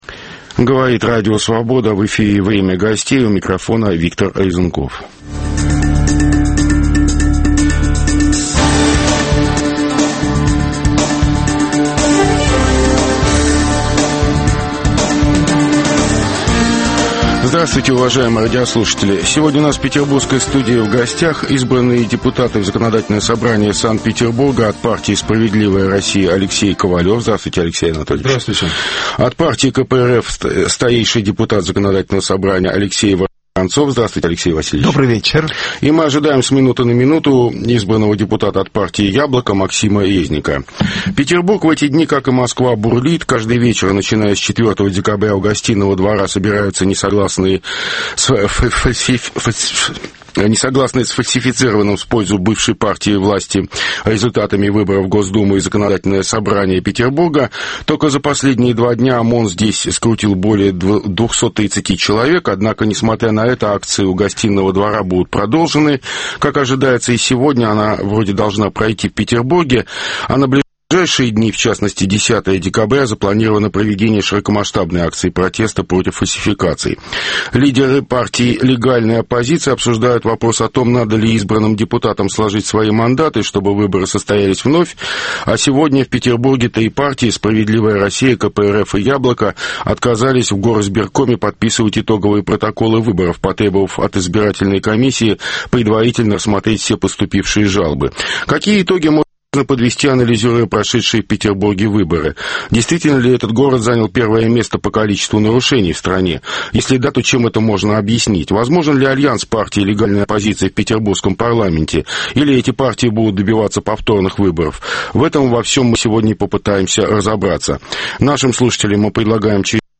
Что ожидать от "системной" оппозиции в новом парламенте Петербурга? Обсуждают: депутаты Законодательного собрания 5-го созыва: Алексей Ковалев /"Справедливая Россия"/, Владимир Дмитриев /КПРФ/ и Максим Резник /"Яблоко"/.